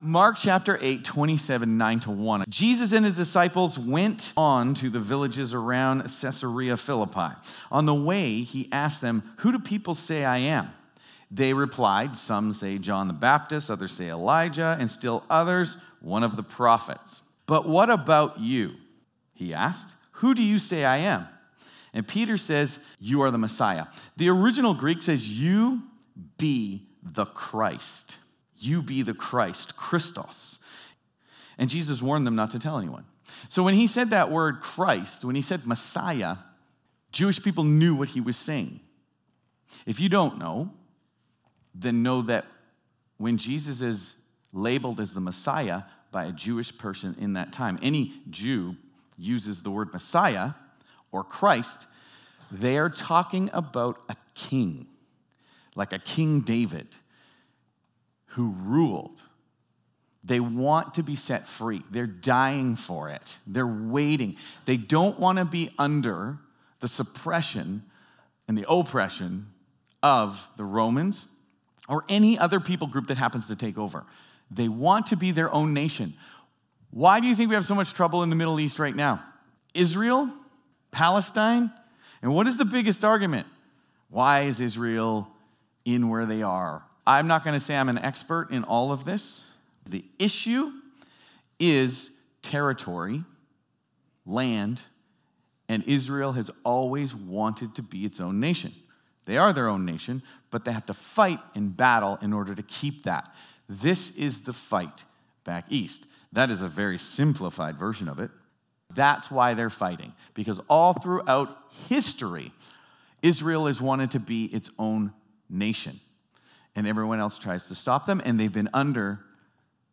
Sermons | Westwinds Community Church